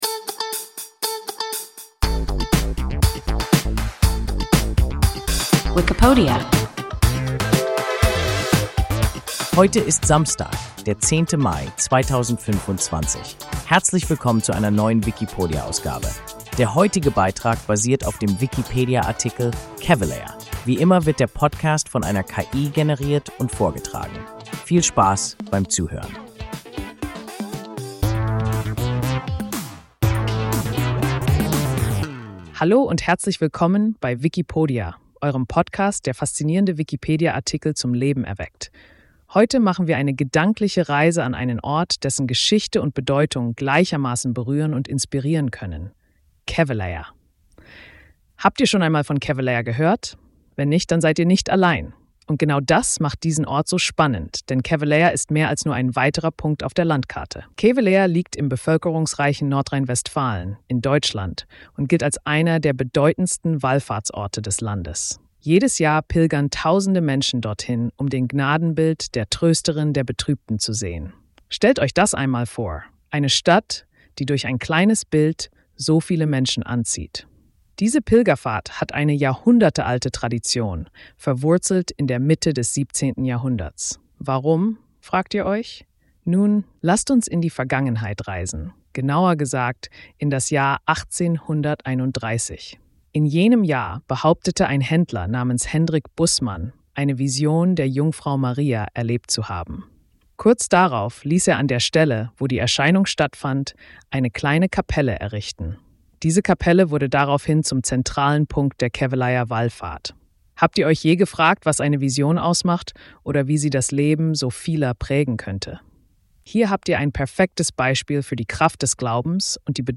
Kevelaer – WIKIPODIA – ein KI Podcast